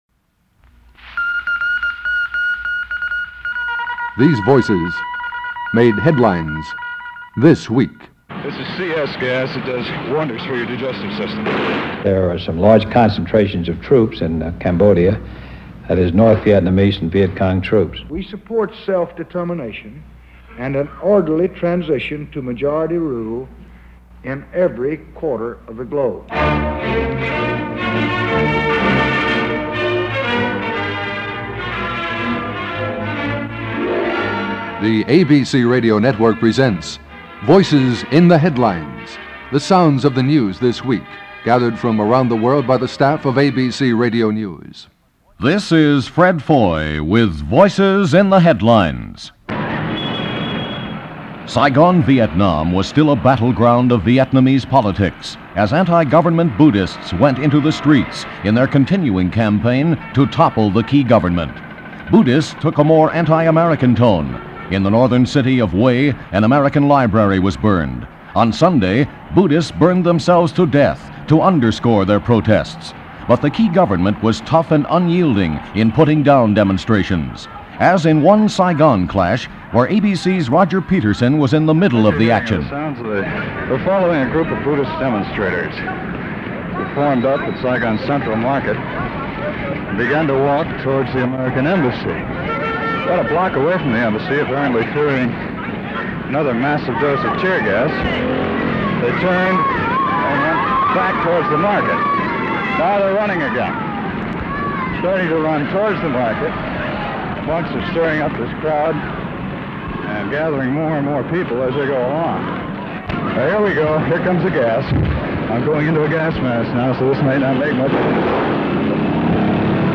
News for the week, ending on this May 29th in 1966, was mostly about our inevitable plunge into the quagmire called Vietnam. Rioting had erupted in Saigon between anti-government protestors and the junta government of Nguyen Cao Ky.